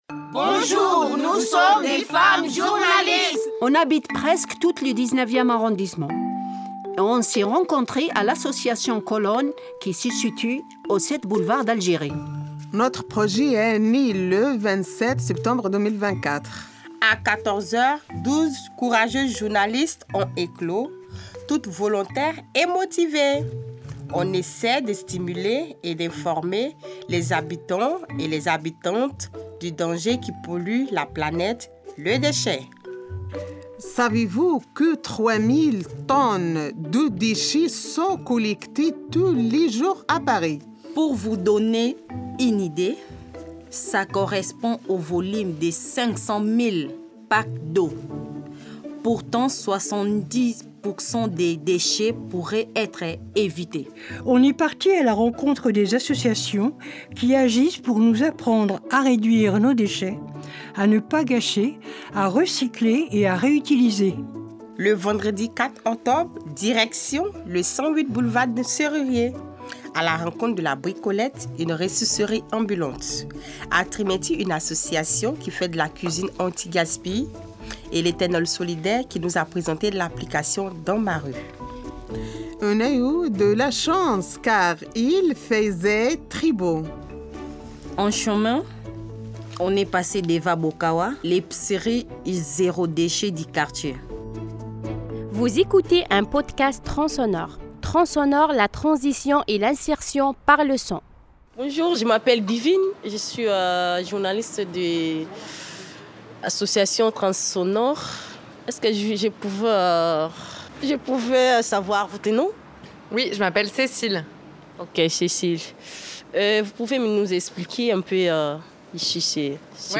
Nous sommes des femmes journalistes.